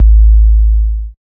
90 808 KIK-R.wav